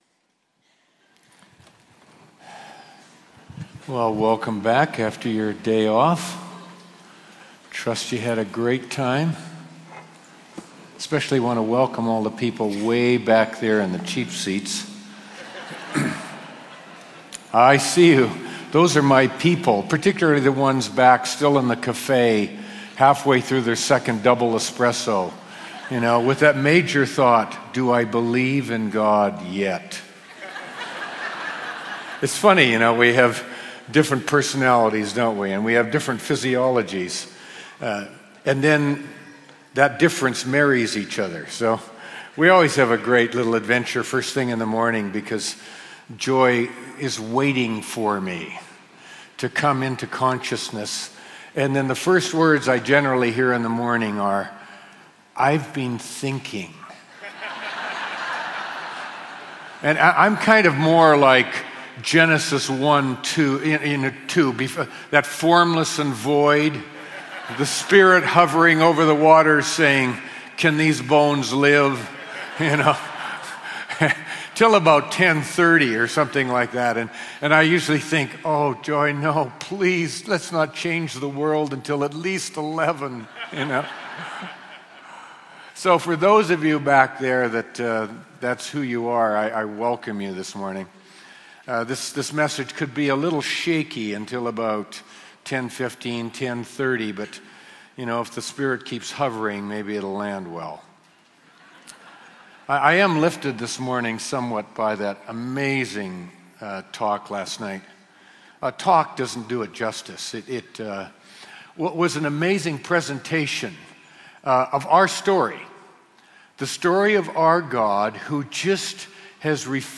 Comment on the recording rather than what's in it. Teaching from New Wine Christian Conference – for all to share.